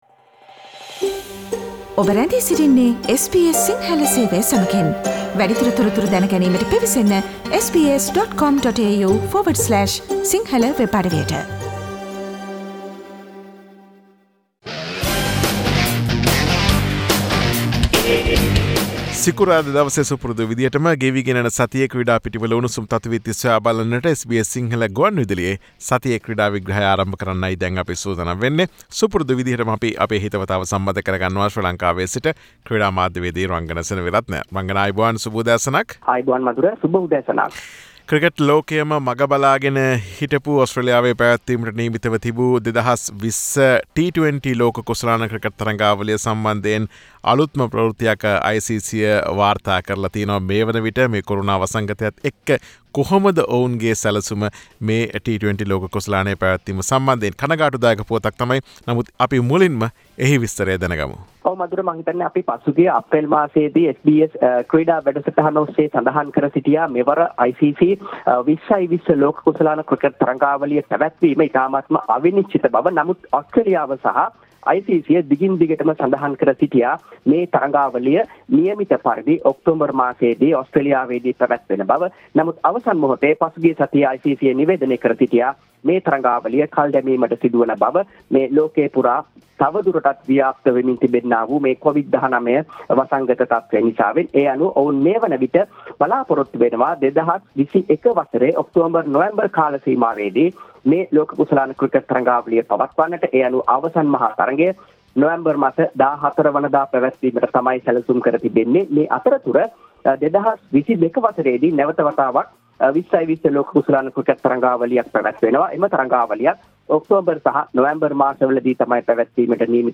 SBS Sinhalese Sports Wrap with Sports Journalist